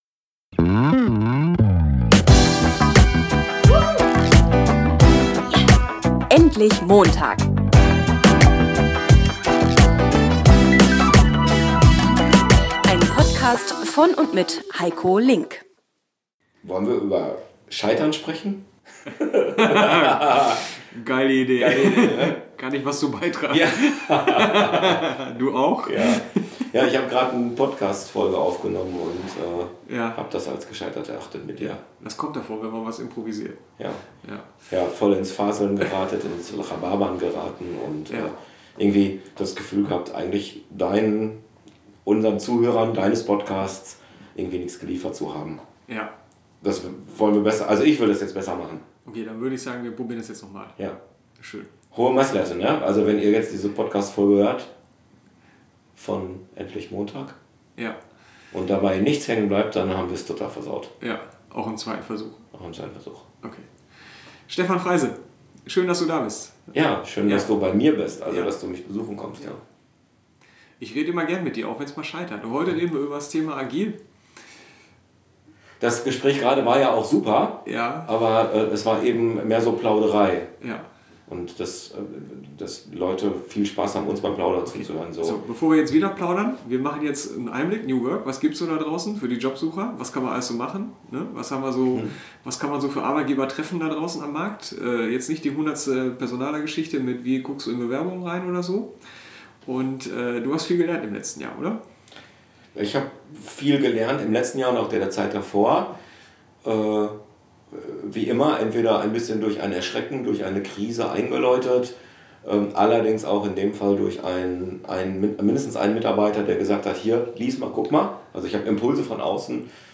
Im Podcast-Interview sprechen wir übers Scheitern. Über Lernen, das durch Erschrecken und Krisen eingeläutet wird. Darüber, ob es alle Mitarbeiter toll finden, in einem agilen, selbstorganisierten Team zu arbeiten.